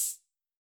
UHH_ElectroHatC_Hit-21.wav